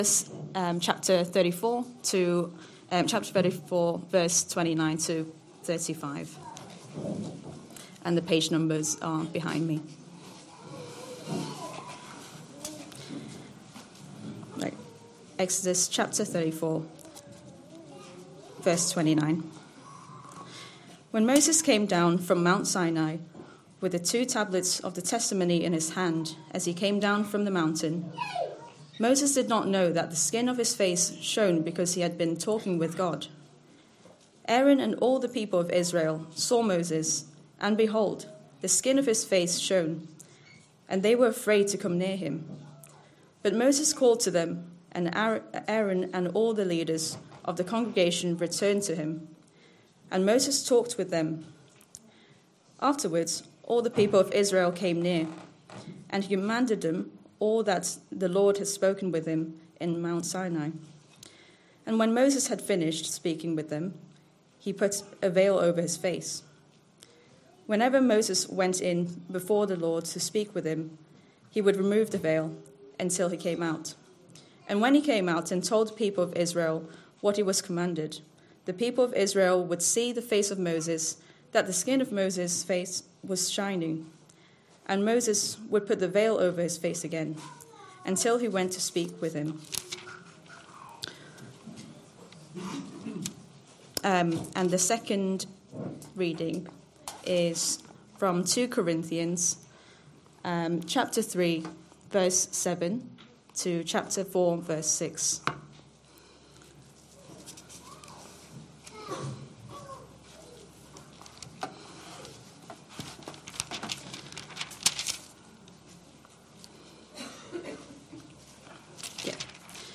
Sunday AM Service Saturday 22nd February 2025 Speaker